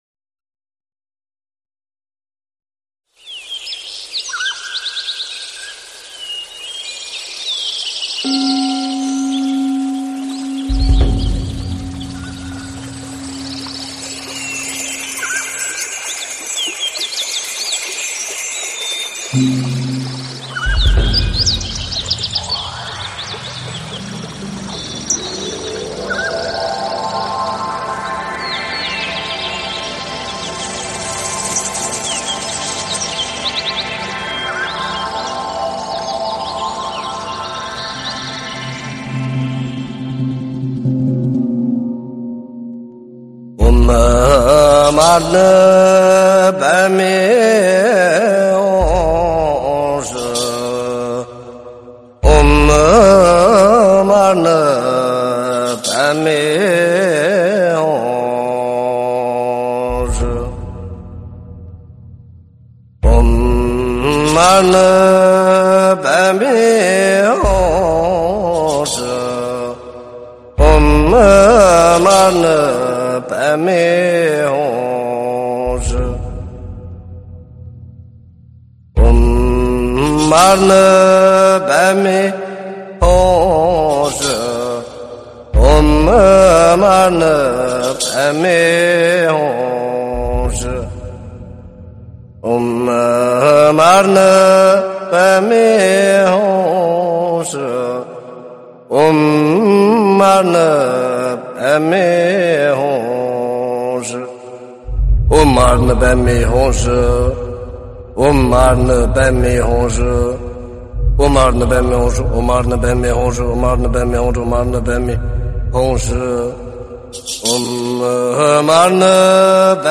观音菩萨心咒 诵经 观音菩萨心咒--天籁梵音 点我： 标签: 佛音 诵经 佛教音乐 返回列表 上一篇： 观音偈 下一篇： 观音菩萨偈 相关文章 金刚般若波罗密经-上--陕西歌舞剧院民乐队 金刚般若波罗密经-上--陕西歌舞剧院民乐队...